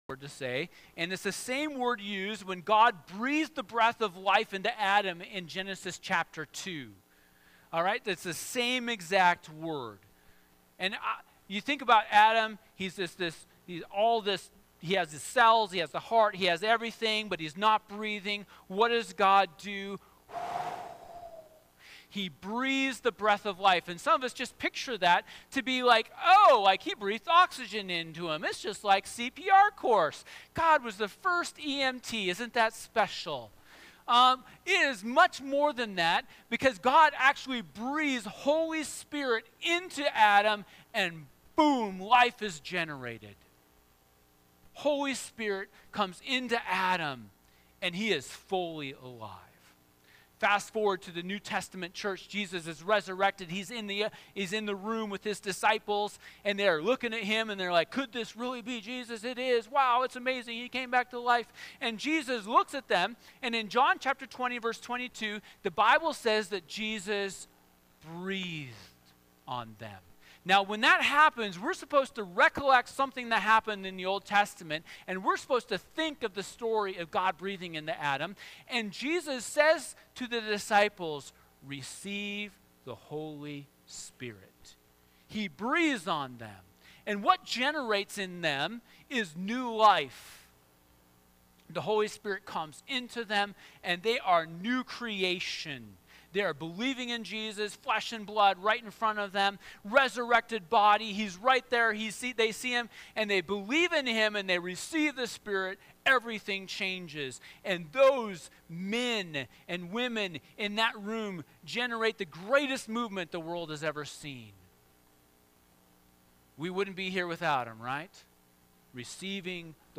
Sermons | Parkhill Church